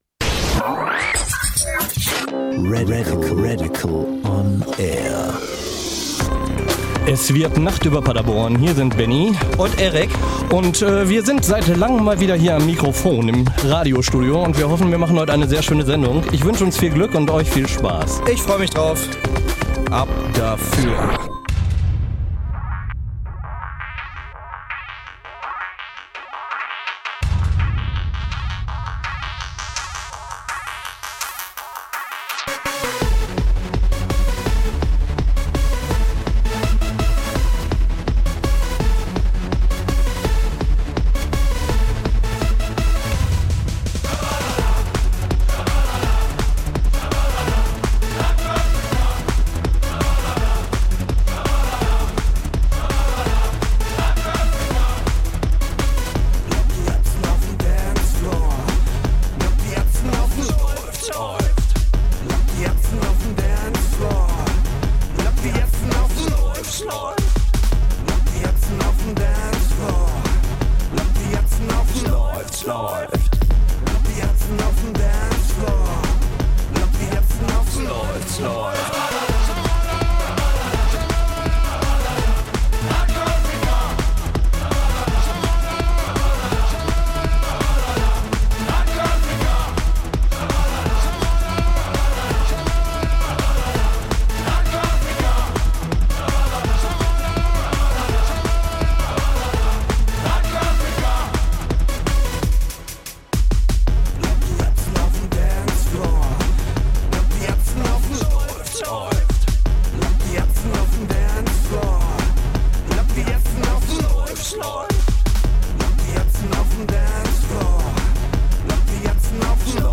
Start Mystery X - Auf der Suche nach Paul; Folge 1, im Studio THE FABULOUS P-BOIZ